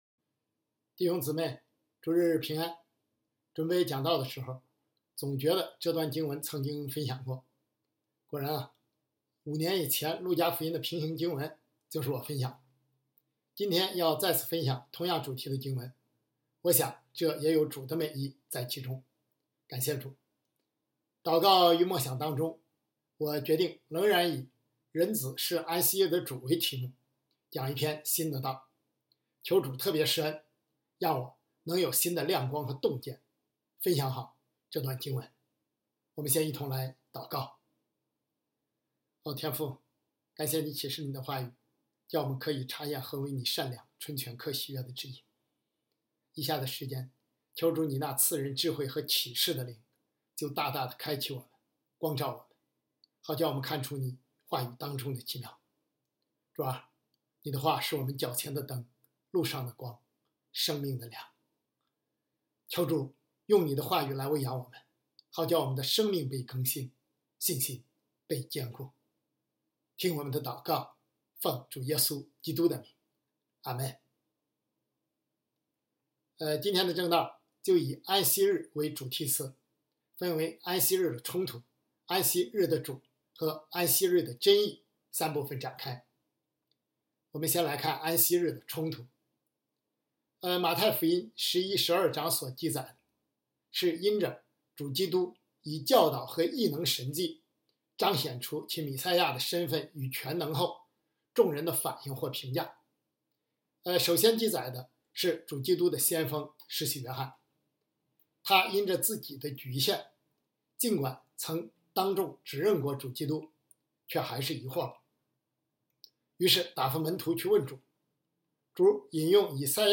基督教北京守望教会2025年6月22日主日敬拜程序